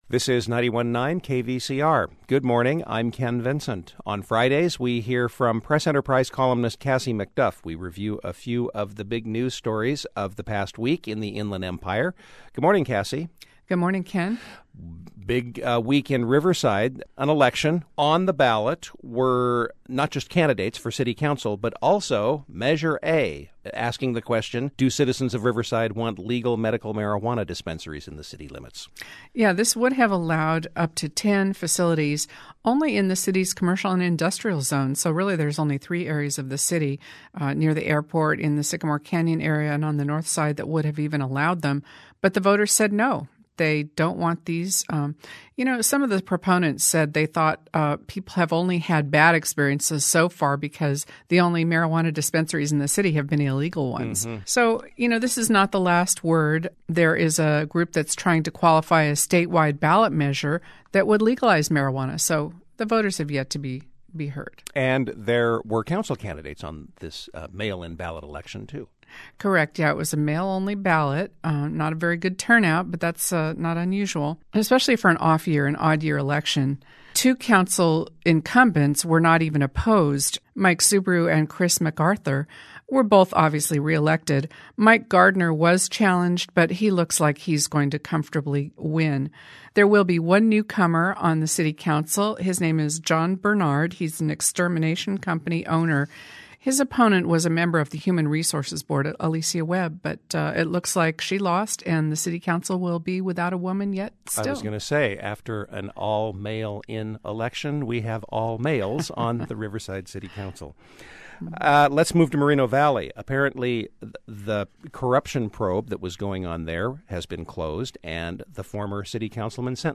Local Civic Affairs